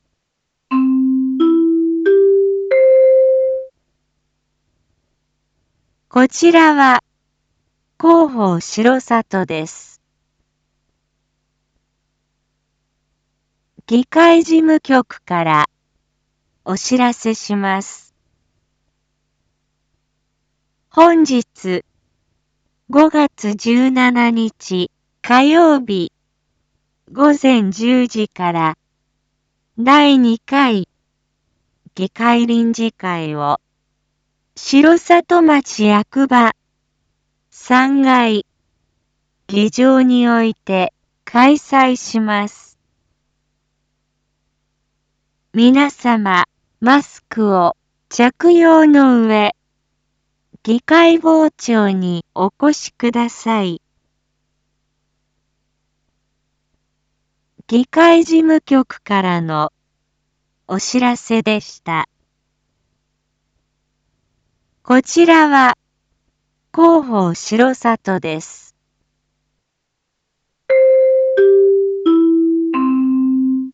一般放送情報
Back Home 一般放送情報 音声放送 再生 一般放送情報 登録日時：2022-05-17 07:01:20 タイトル：R4.5.17 7時放送分 インフォメーション：こちらは広報しろさとです。